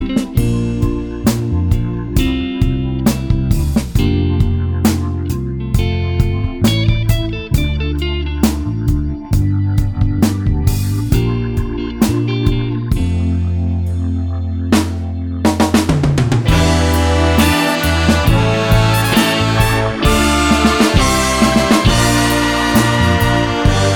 Live Pop (1960s) 3:20 Buy £1.50